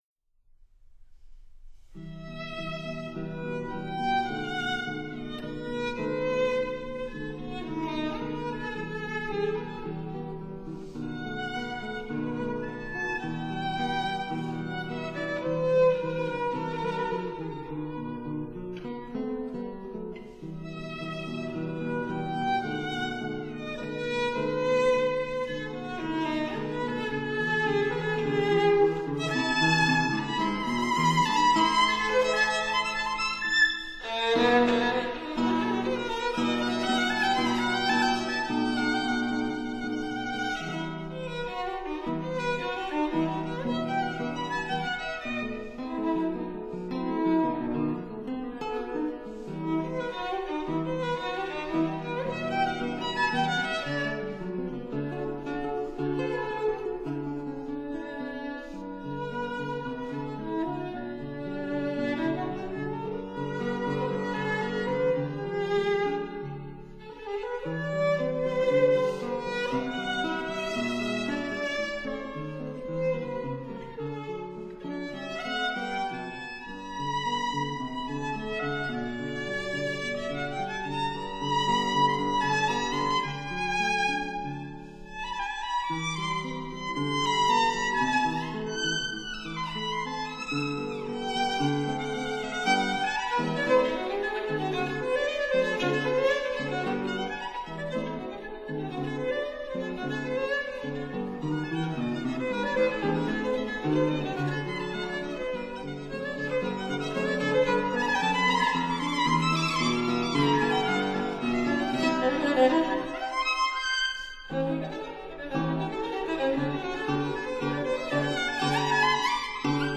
Violin
Guitar
(Period Instruments)